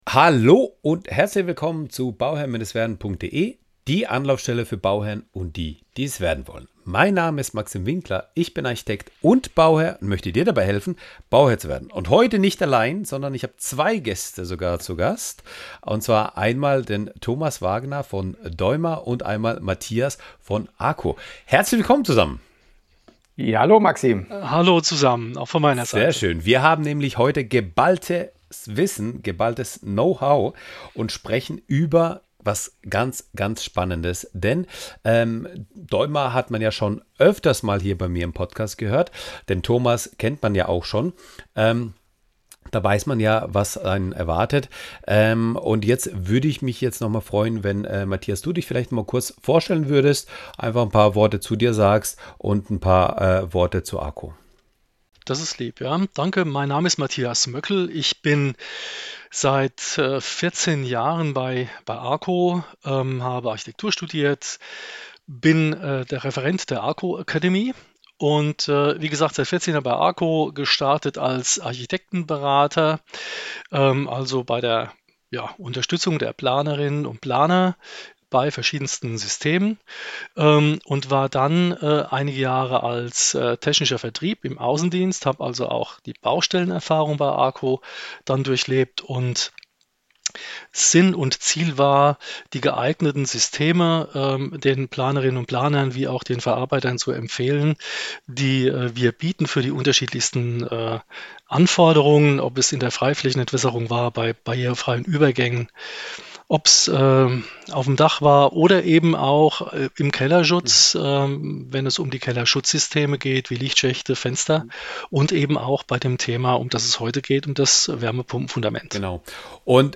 Erfahre, wie Kondensat, Abdichtung und elektrische Anschlüsse bei der Installation von Wärmepumpen sicher, effizient und optisch ansprechend gelöst werden. Entdecke, wie durch flexible Systeme Schnittstellen zwischen Gewerken minimiert und Fehlerquellen im Bauprozess reduziert werden. Dieses Gespräch bietet wertvolle Tipps für Bauherren, Energieberater, Architekten und Handwerker – ideale Impulse für alle, die eine Wärmepumpe nachrüsten oder im Neubau planen.